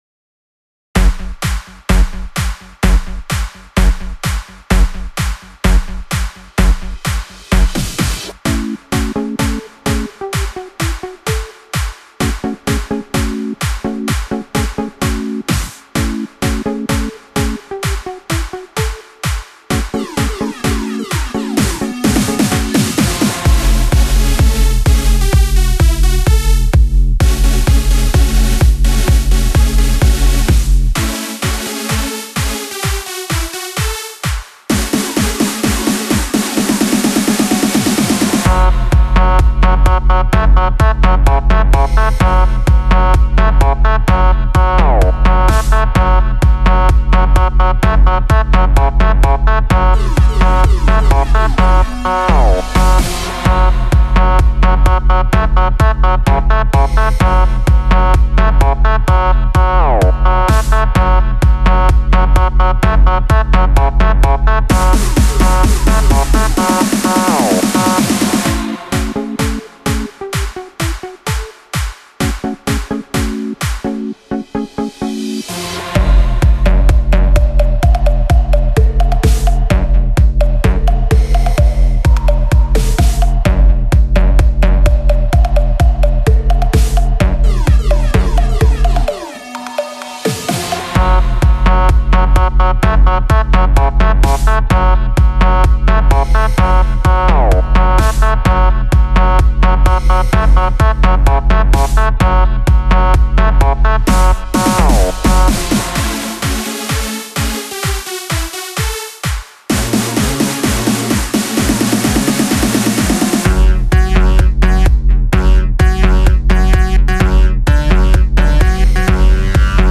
è un EDM pura da ascoltare ai massimi volumi